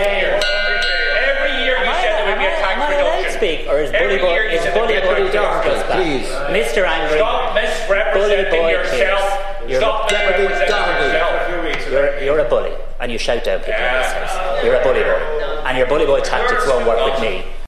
It came amid a heated exchange in the Dáil over the budget and cost of living crises.
It led to loud exchanges between Simon Harris and Pearse Doherty, in which Deputy Doherty was referred to as ‘bully boy Pearse’: